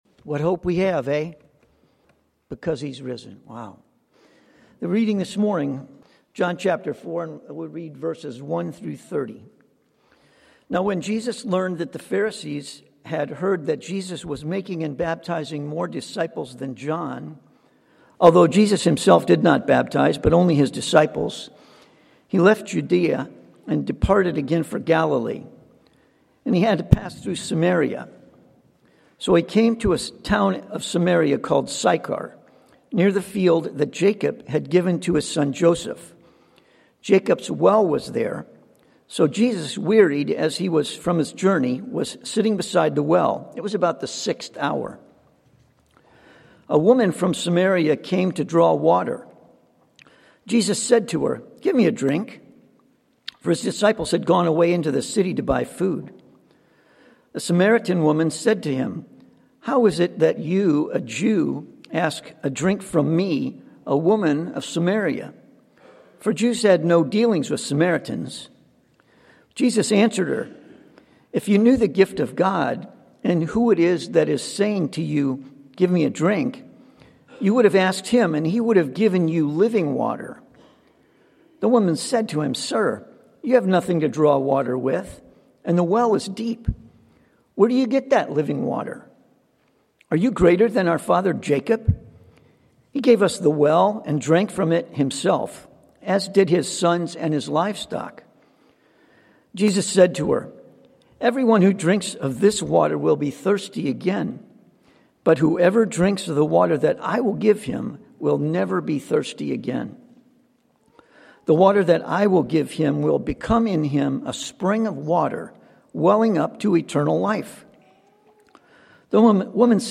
Passage: John 4:1-30 Sermon